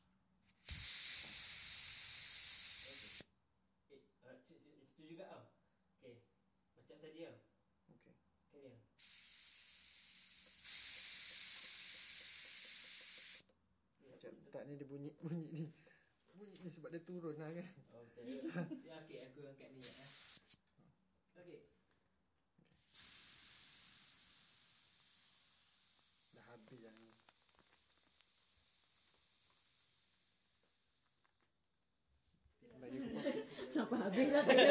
描述：自行车刺破轮胎气体声音环境自然周围的现场录音 环境foley录音和实验声音设计。
标签： 声音 自然 刺破 轮胎 周围 环境 气体 现场记录 自行车
声道立体声